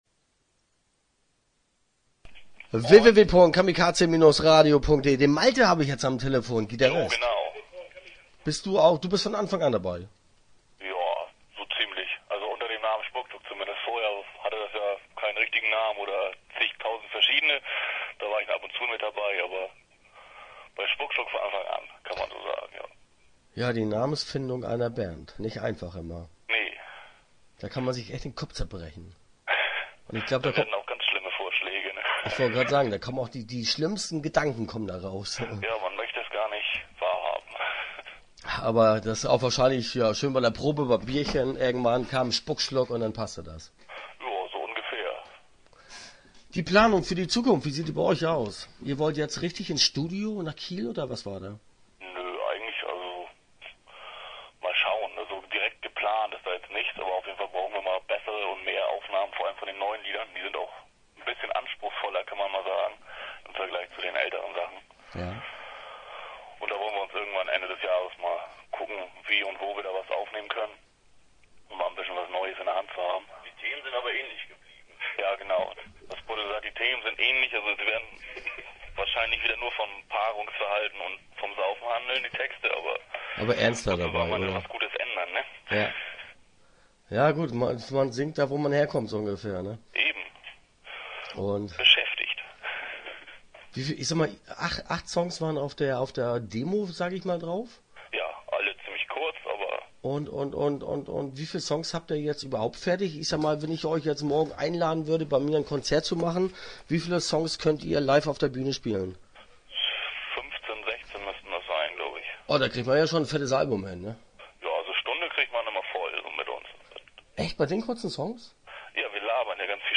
Start » Interviews » Spuckschluck